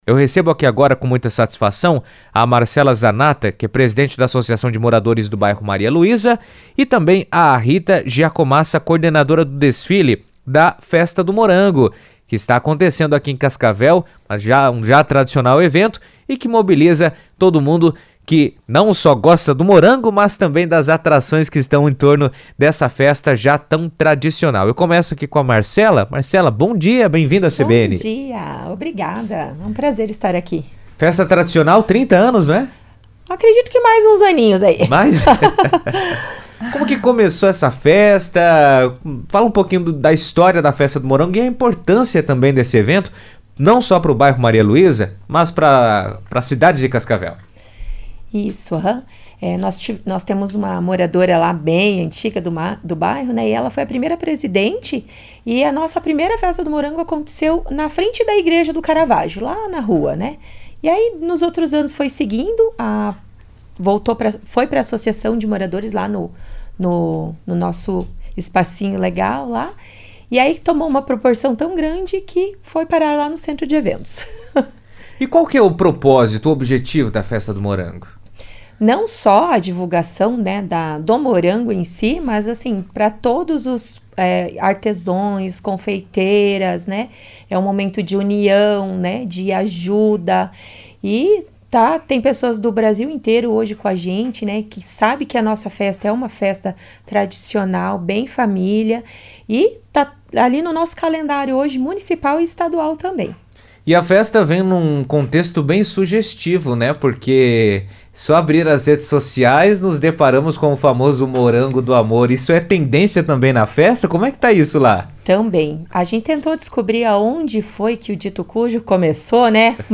A 23ª Festa do Morango está movimentando Cascavel com gastronomia e cultura no Centro de Convenções e Eventos, onde mais de 100 expositores participam da programação que vai até o próximo domingo (10). Em entrevista à rádio CBN